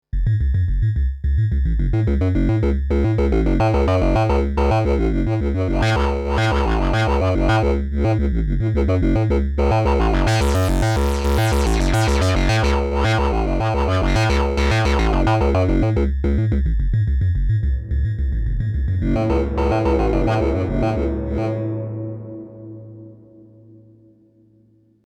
Прикольная демочка, завязанная на резонансе)